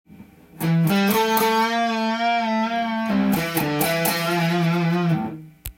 ⑤のフレーズは普通のEマイナーペンタスケールと思いきや
チョーキングを半音のハーフチョーキングにしているので
ブルーノートと言われる渋くて泥臭い音を使っています。
どこまでも渋く弾いていますね。